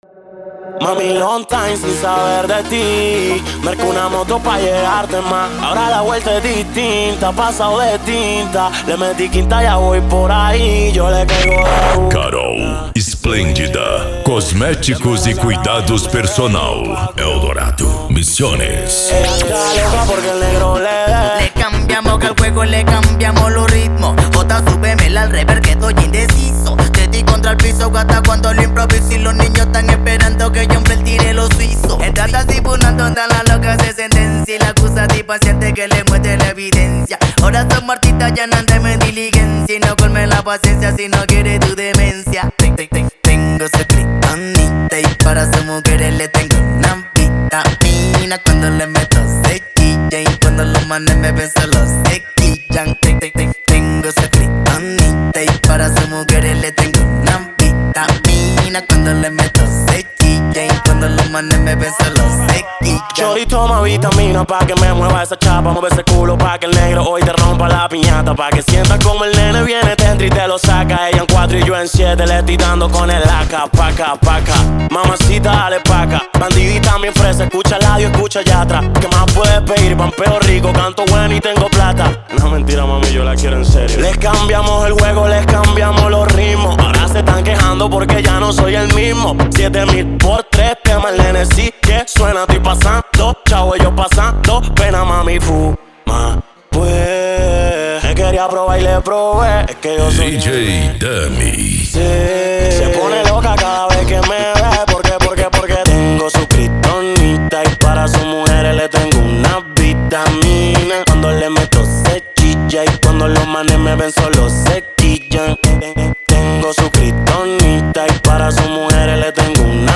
Arrocha
Funk
Reggaeton